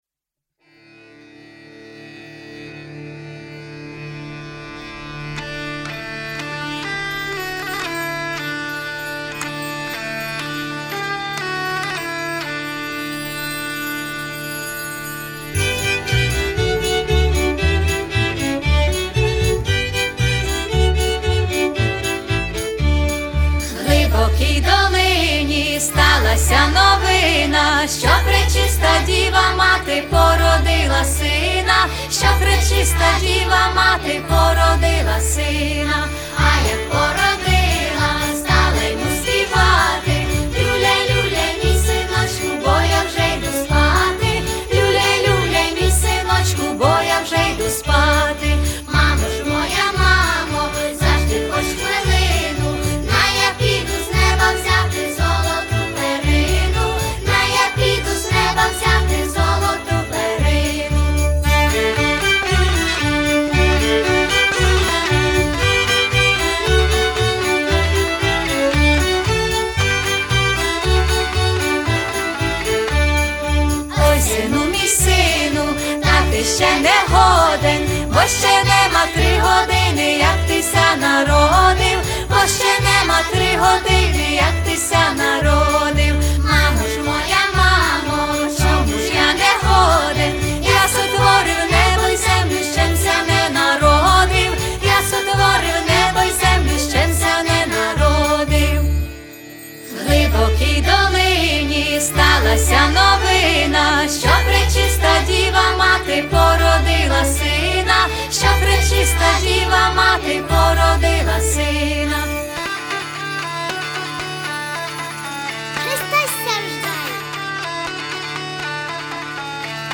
трогательная украинская народная песня
эмоциональная манера